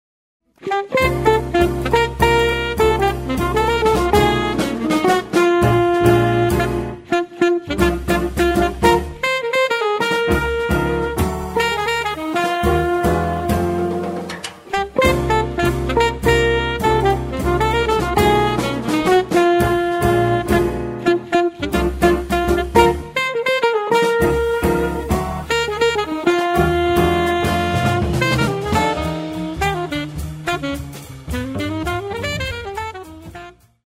sax alto
chitarra elettrica
piano
contrabbasso
batteria